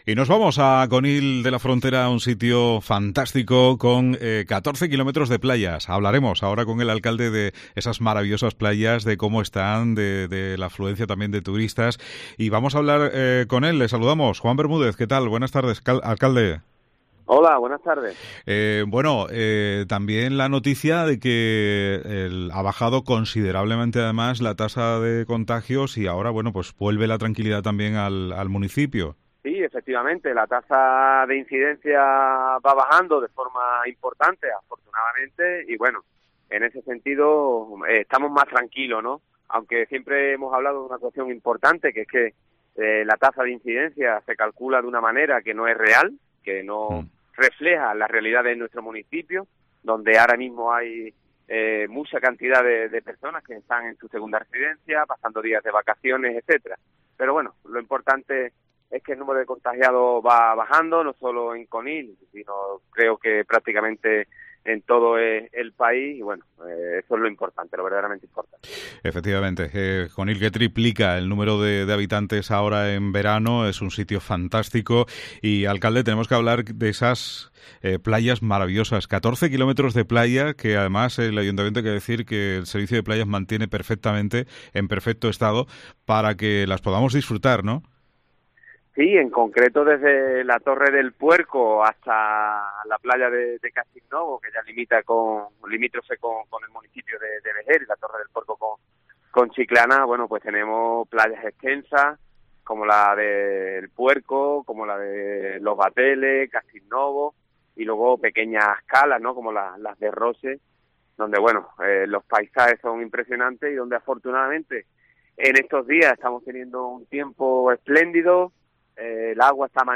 Juan Bermúdez, Alcalde de Conil sobre las playas del municipio y la temporada de verano